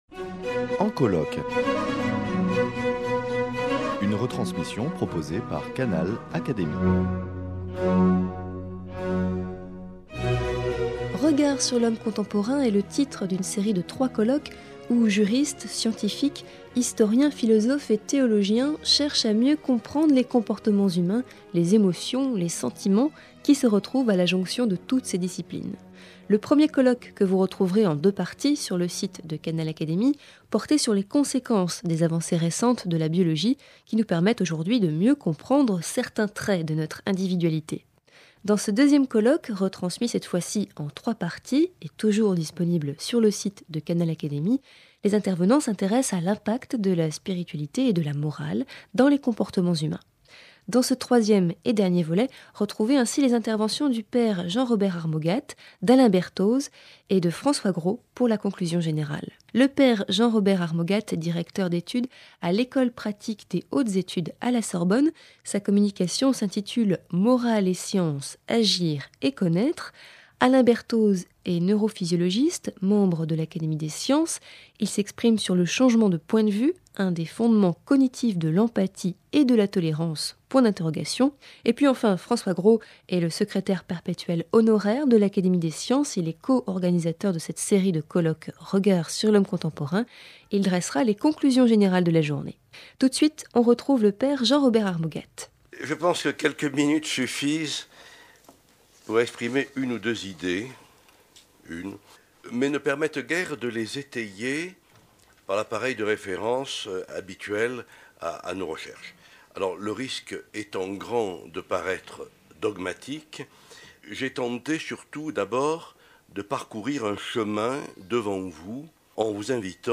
Qu’ils soient philosophe, théologien, juriste ou scientifique, les intervenants de ce colloque s’intéressent aux dimensions morales, spirituelles et du droit qui font partie intégrante des représentations psychiques et des comportements humains.
Canal Académie retransmet ici le deuxième colloque en trois émissions.